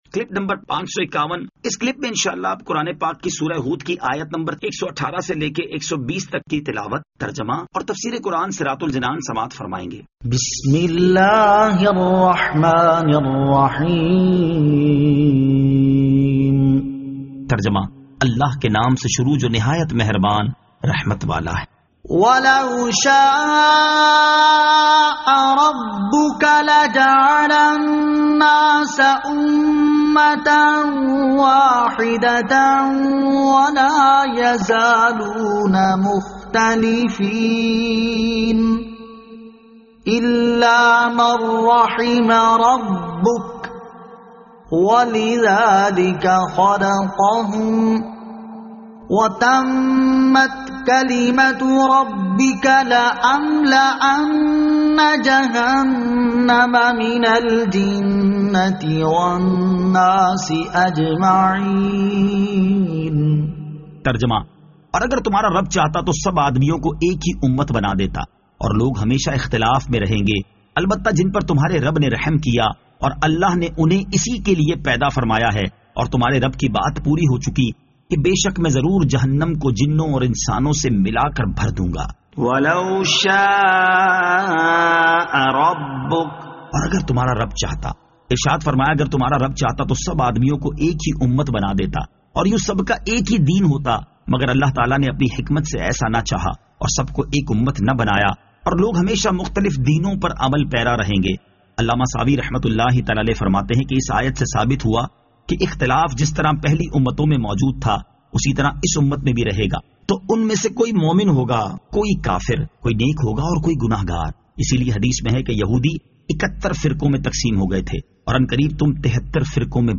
Surah Hud Ayat 118 To 120 Tilawat , Tarjama , Tafseer